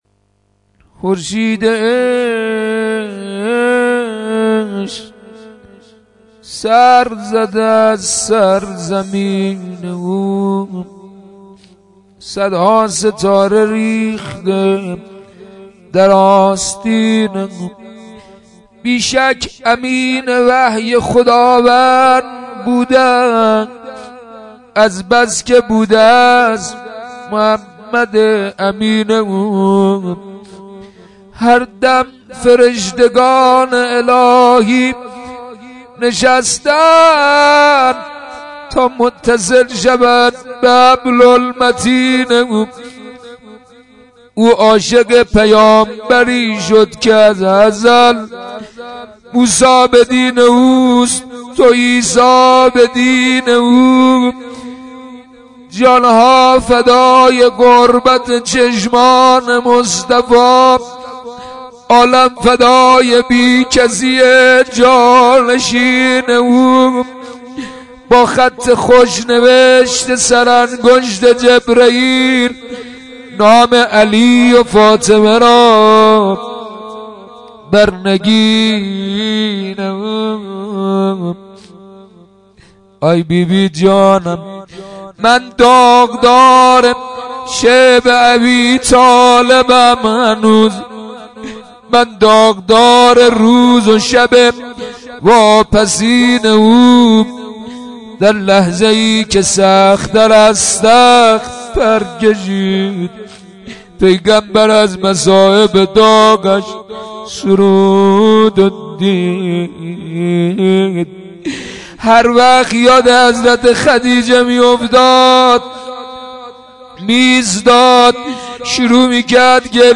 دعای مجیر